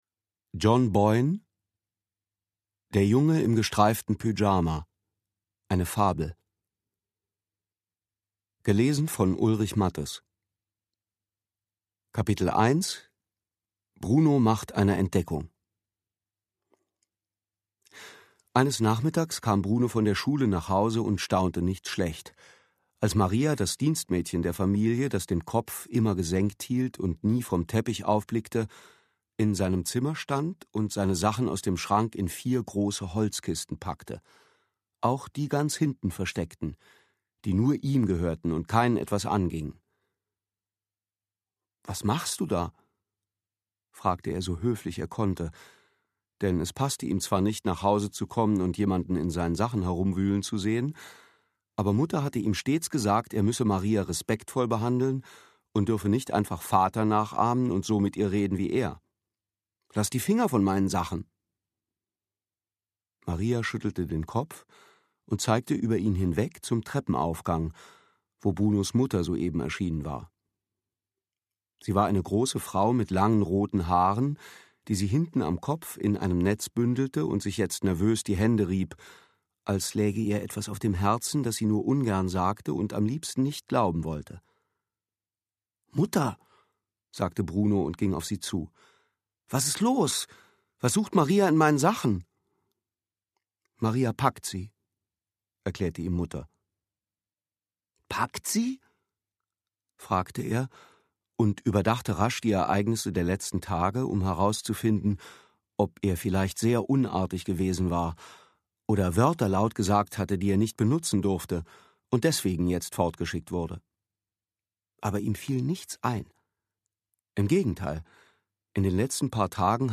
Der Junge im gestreiften Pyjama (DAISY Edition) John Boyne (Autor) Ulrich Matthes (Sprecher) Audio-CD 2008 | 1.